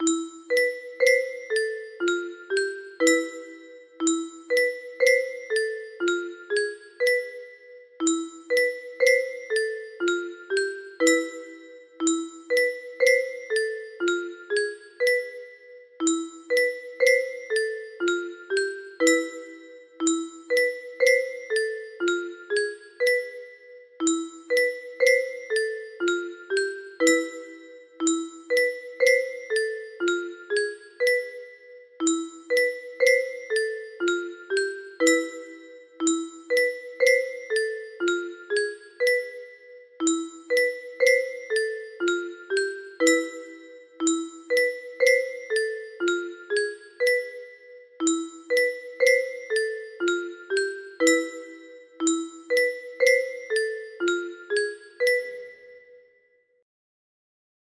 Unquiet Objects music box melody